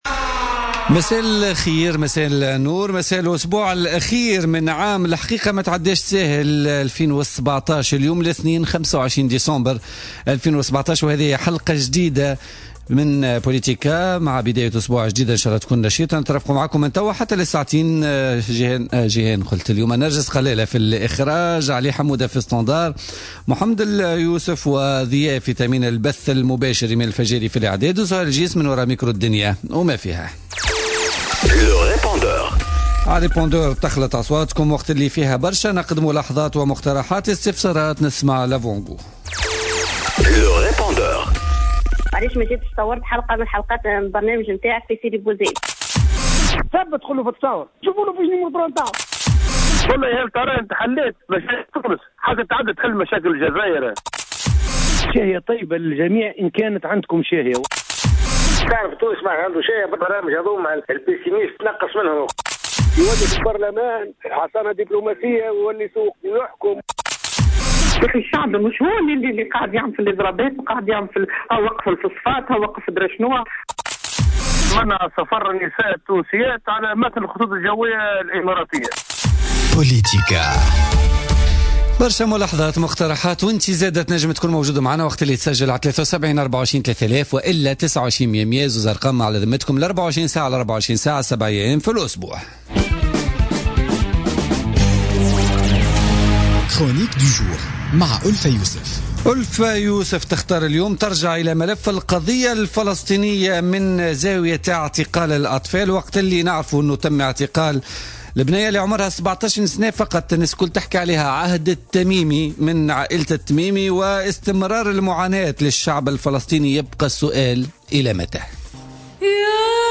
سميرة مرعي نائبة رئيس حزب أفاق تونس ضيفة بوليتيكا